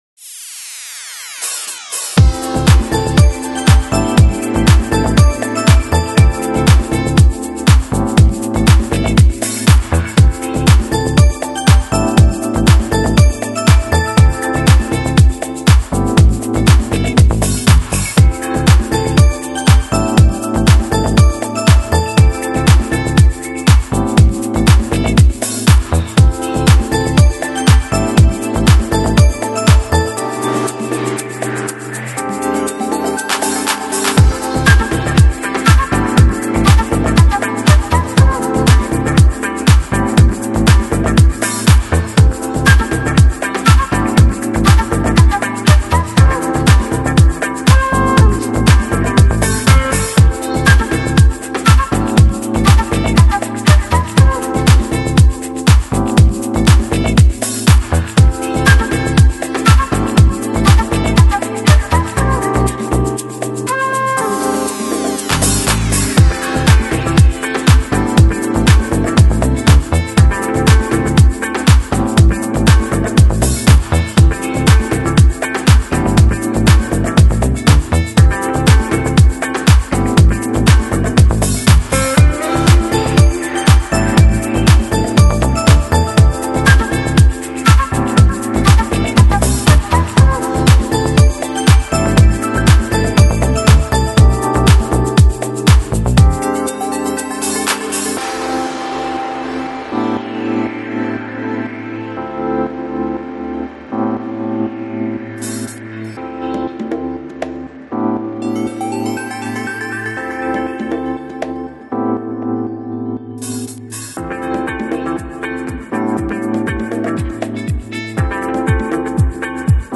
Жанр: Chill Out, Downtempo, Soulful House, Chill House